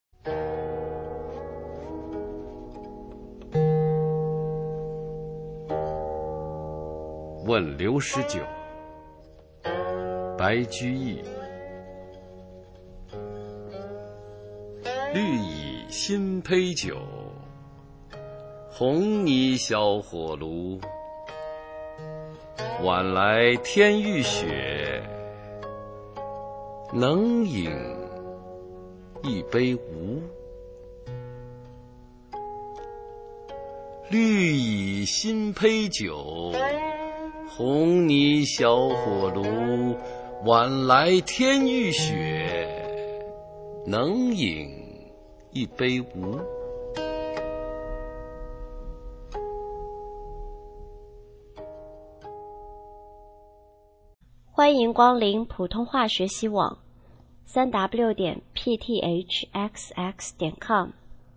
普通话美声欣赏：问刘十九